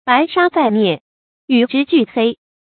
bái shān zài niè，yǔ zhī jù hēi
白沙在涅，与之俱黑发音